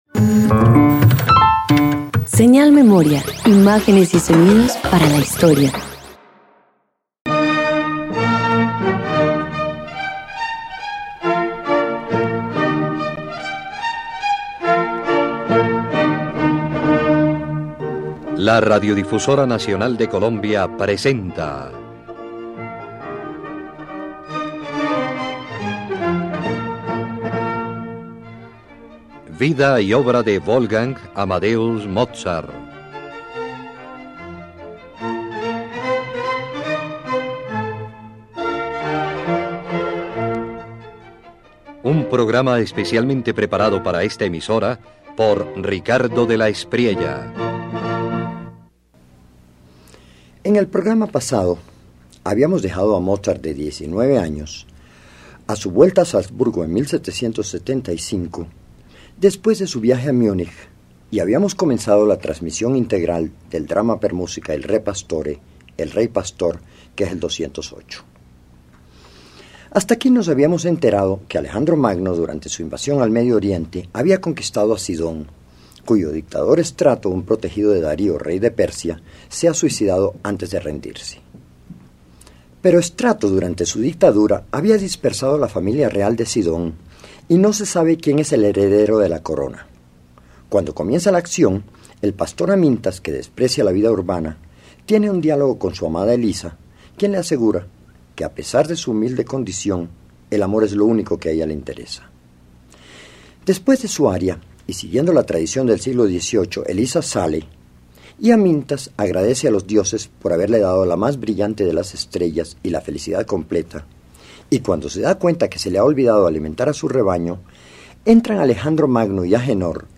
El rey pastor narra cómo Amintas, un hombre humilde y heredero oculto al trono de Sidón, prefiere la sencillez y el amor de Elisa antes que el poder. Con música luminosa y apasionada, el joven Mozart exalta la pureza y la libertad del alma.